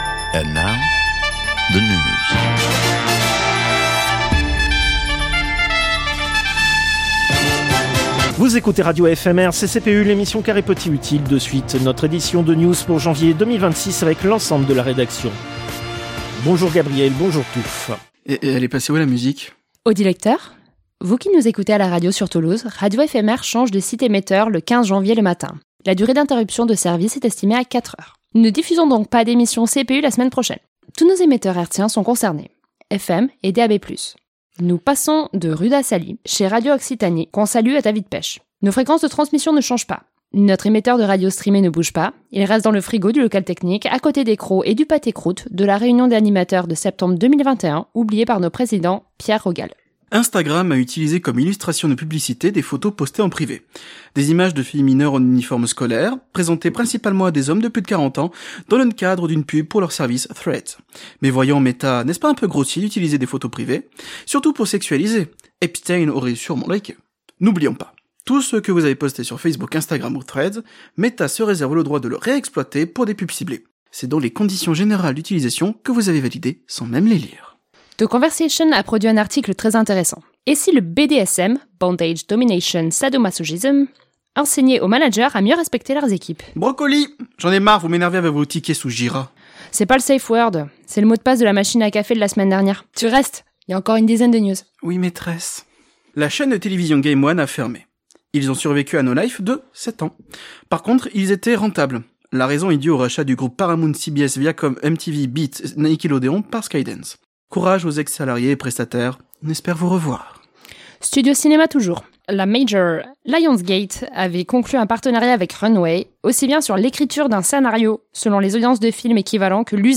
Extrait de l'émission CPU release Ex0228 : lost + found (janvier 2026).